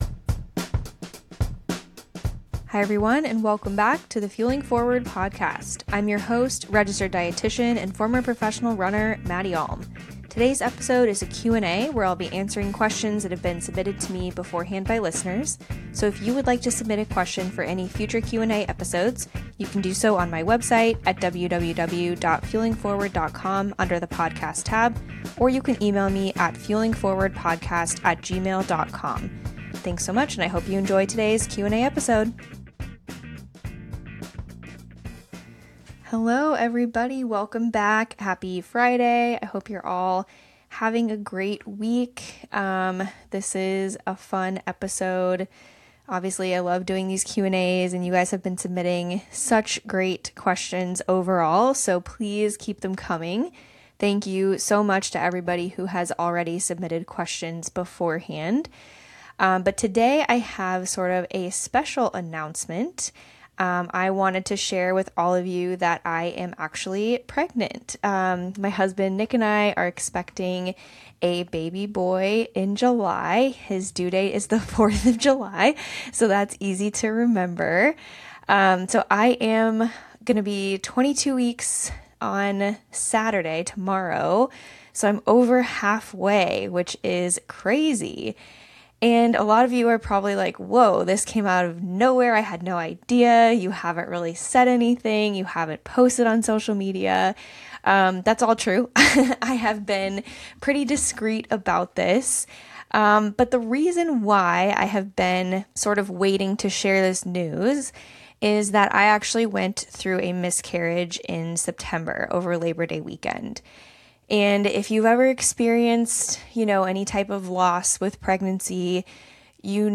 This episode is a Q&A episode where I answer questions that have been submitted by listeners. Today's episode starts off with a fun personal announcement 👀.